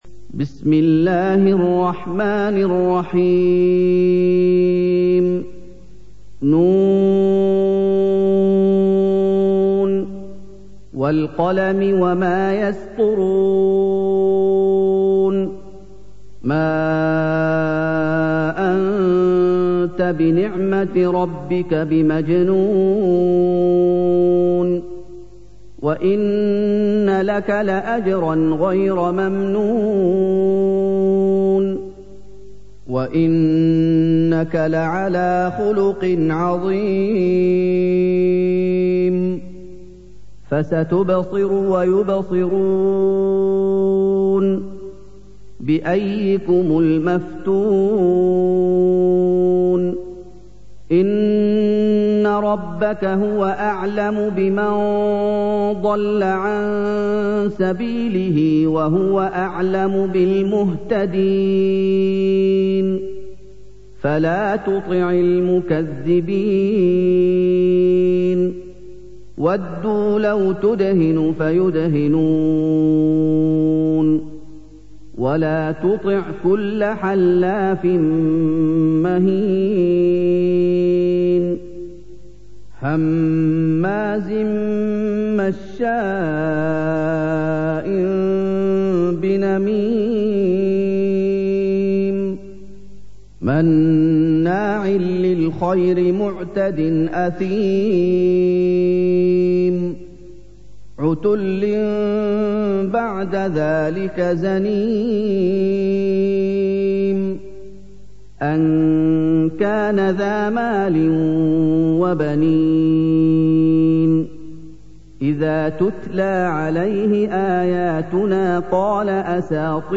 سُورَةُ القَلَمِ بصوت الشيخ محمد ايوب